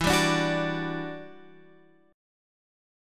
E Minor 9th